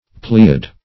Pleiad \Ple"iad\, n.